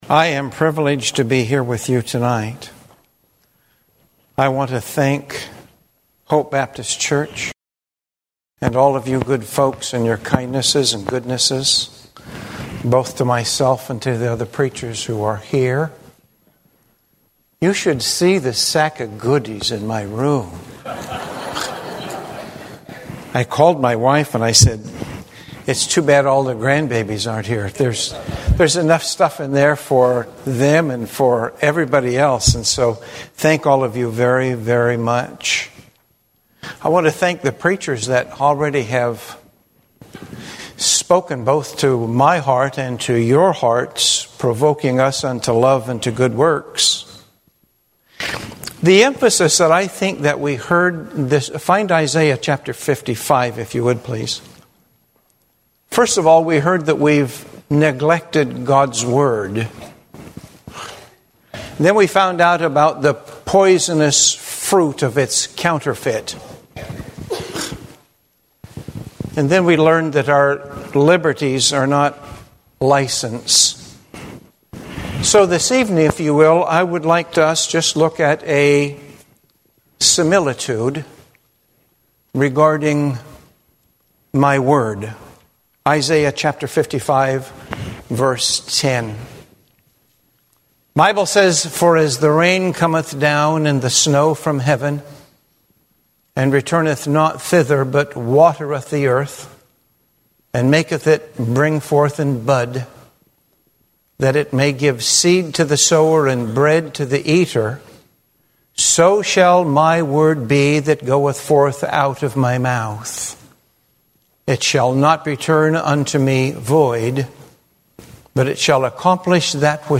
Exodus Service Type: Pastor School Here I Stand Preaching August 8